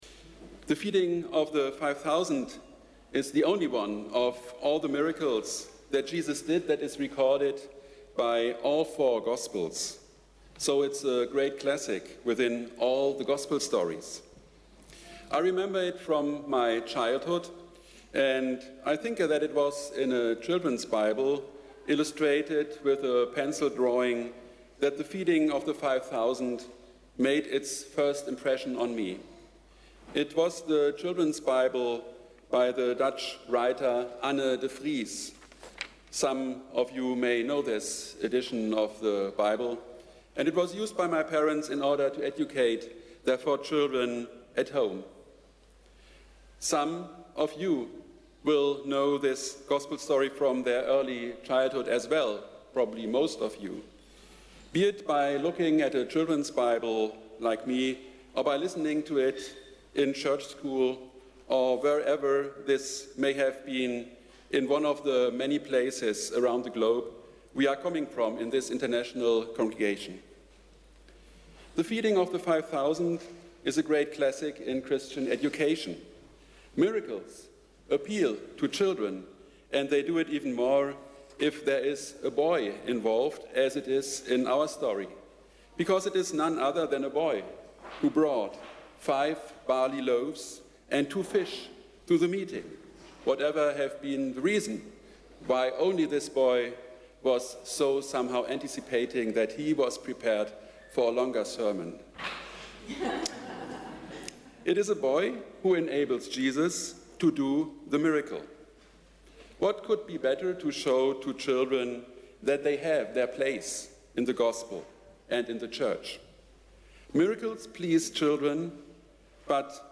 Sermon: ‘The internal miracle’